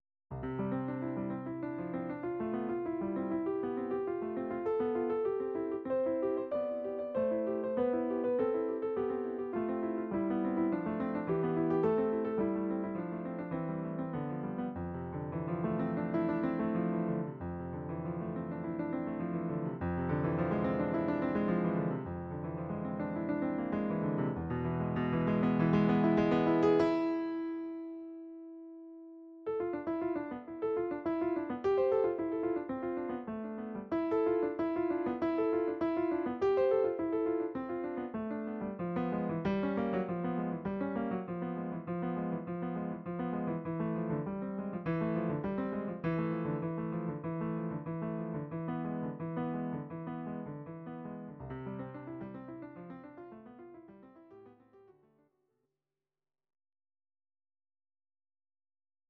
These are MP3 versions of our MIDI file catalogue.
Your-Mix: Instrumental (2074)